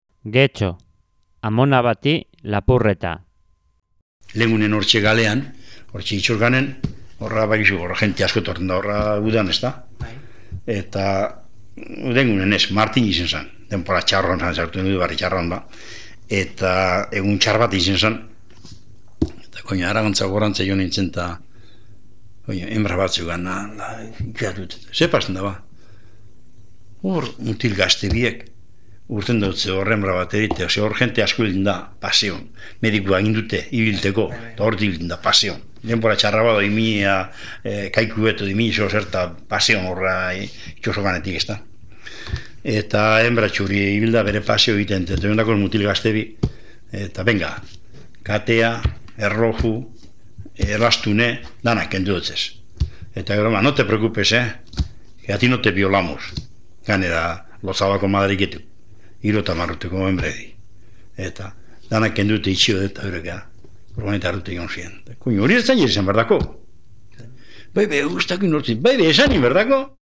1.1. GETXO